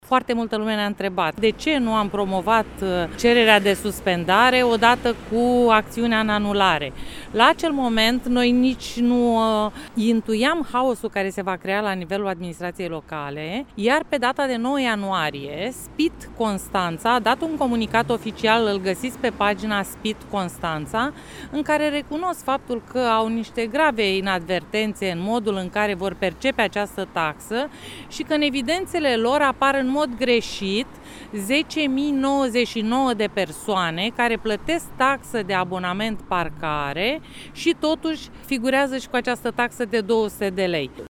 Avocatul detaliază și problemele existente la nivelul Serviciului Public de Impozite și Taxe Constanța: